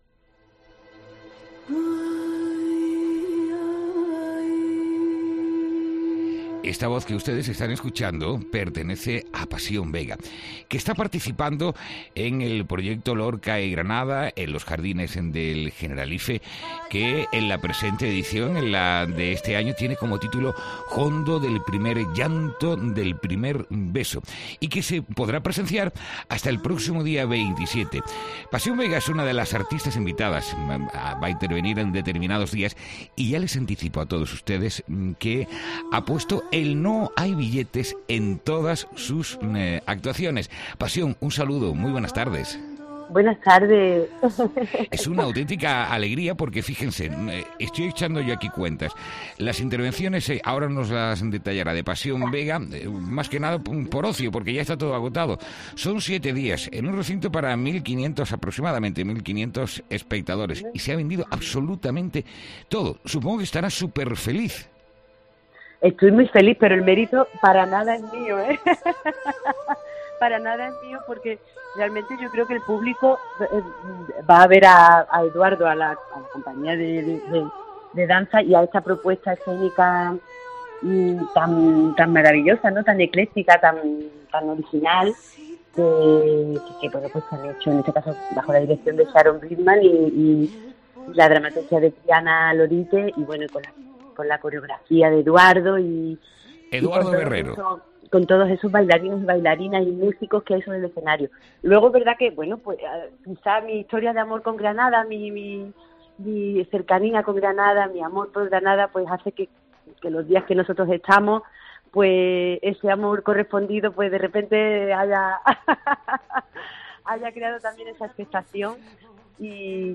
En entrevista en COPE, Pasión Vega ha anunciado que su próximo disco estará precisamente dedicado a Federico García Lorca.